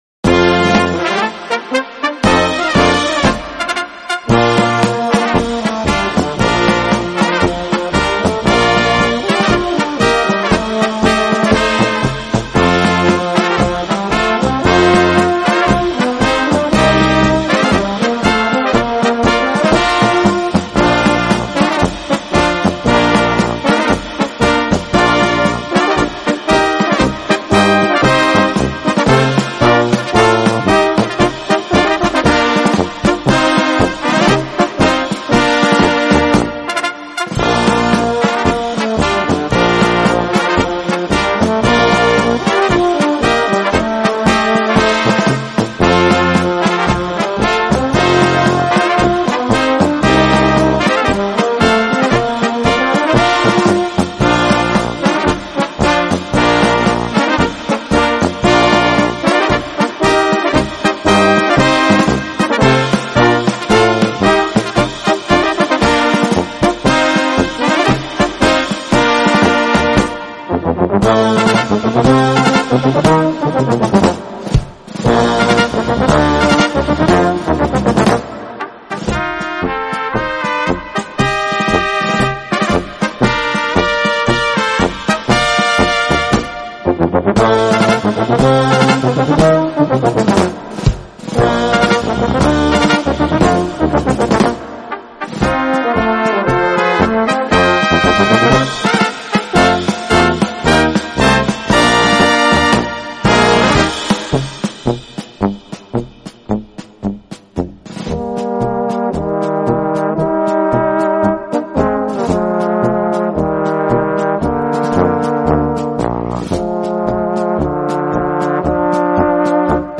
Gattung: Marsch für böhmische Besetzung
Besetzung: Kleine Blasmusik-Besetzung
Spitzenmarsch